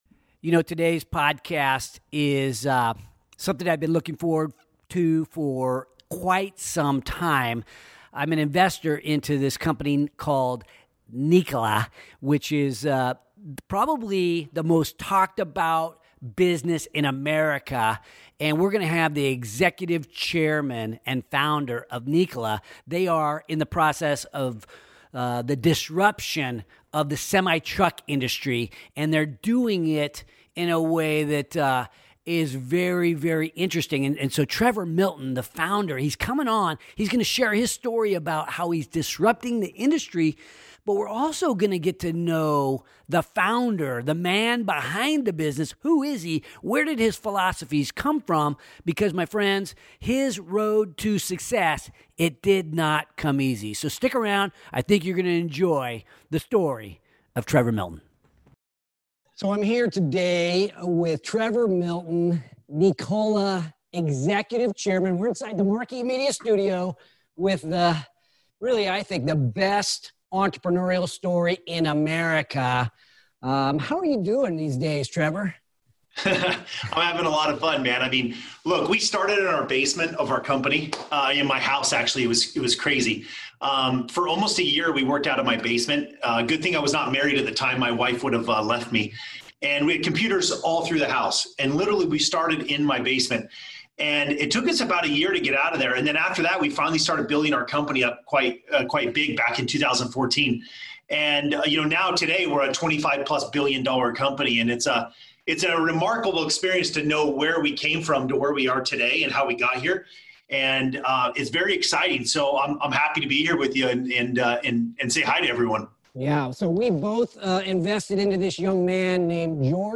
In this week’s podcast I sit down with the most interviewed entrepreneur in America right now, Trevor Milton the Founder and Chief Executive Chairman of Nikola Corporation. We talk about the mindset it takes to be a successful entrepreneur and how Nikola is changing the world with electric and hydrogen powered vehicles.